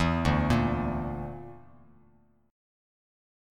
Dsus2#5 chord